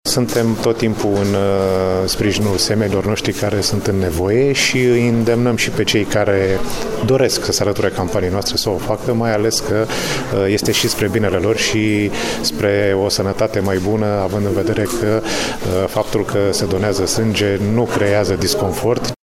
Comandantul Inspectoratului judeţean de Jandarmi, Cristian Ely Paliştan: